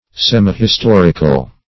Search Result for " semihistorical" : The Collaborative International Dictionary of English v.0.48: Semihistorical \Sem`i*his*tor"i*cal\, a. Half or party historical.